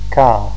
kaa